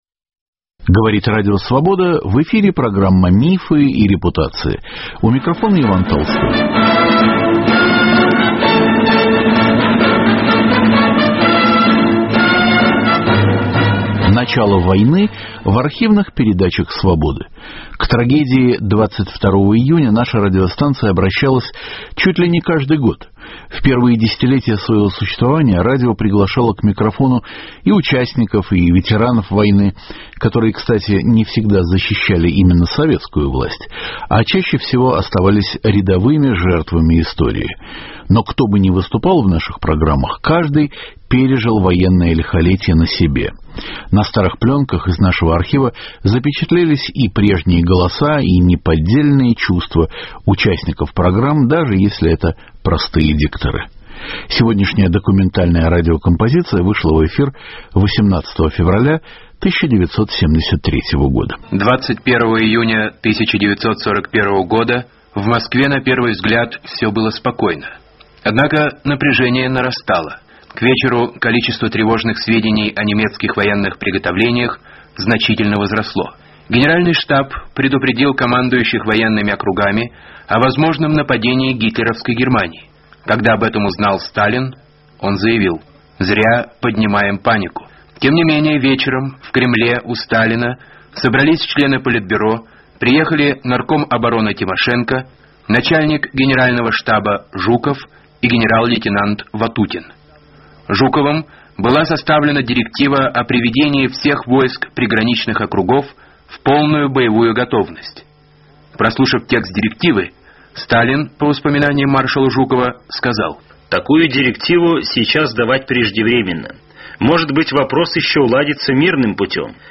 Начало войны в архивных передачах Свободы. Документальные радиокомпозиции 1970-х годов: трагедия 22 июня глазами историков, бардов и детей-подростков. Старые пленки рассказывают.